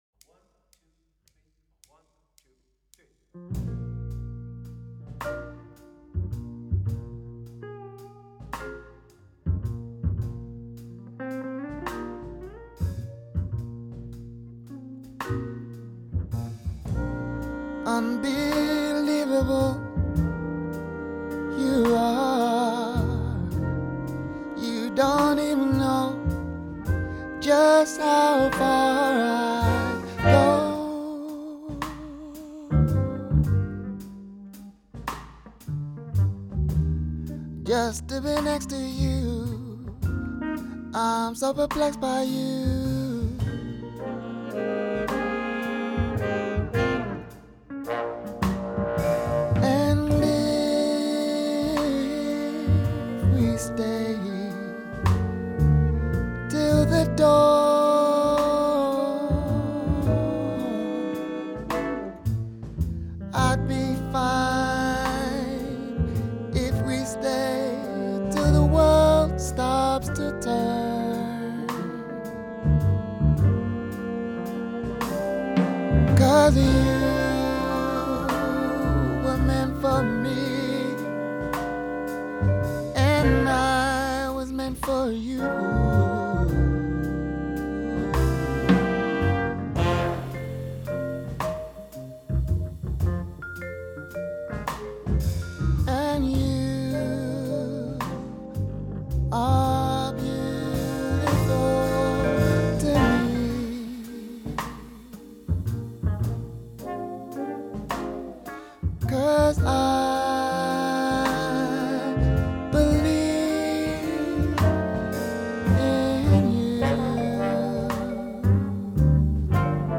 Gospel Version